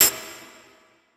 CC - Xn Hat Perc.wav